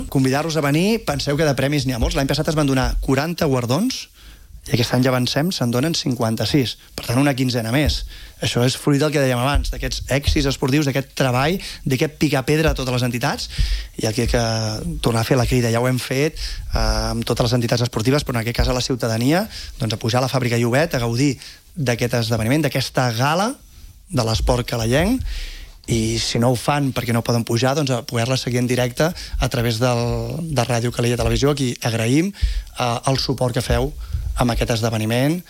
El regidor d’Esports ha aprofitat els micròfons de RCT per a convidar a tothom a pujar demà a la Sala Polivalent de la Fàbrica Llobet Guri.